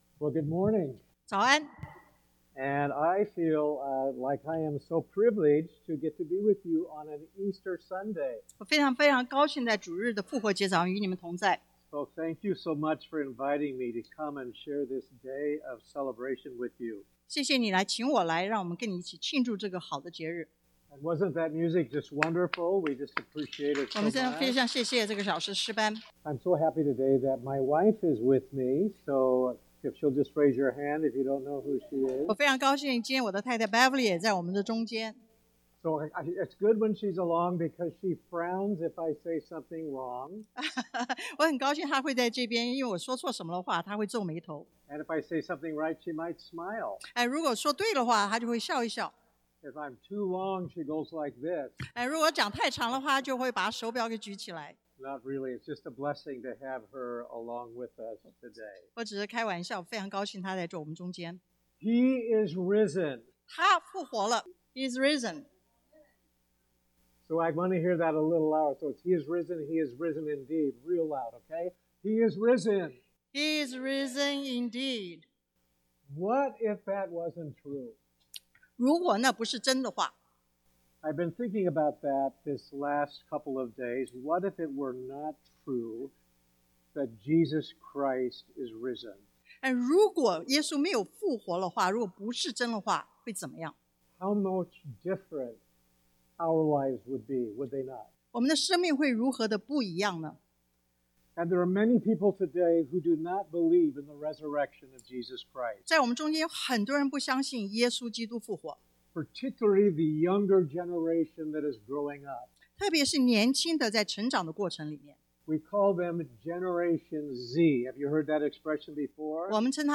50-58 Service Type: Sunday AM Introduction 前言 What gives you hope?